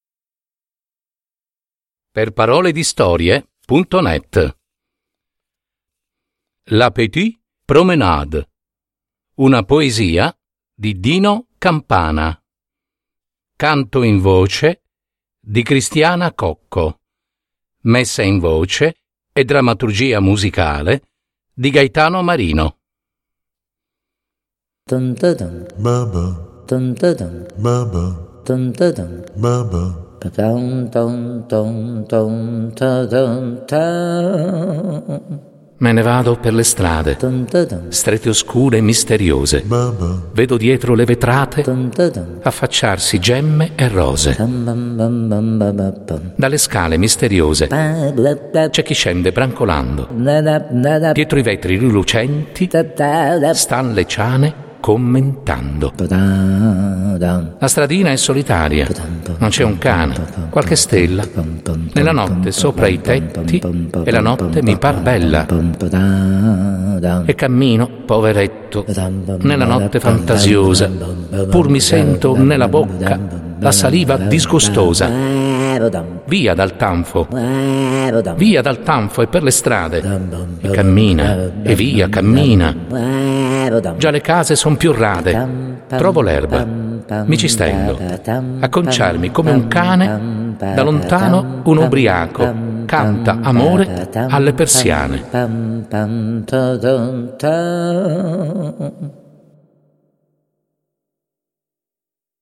Messa in voce
Canto in voce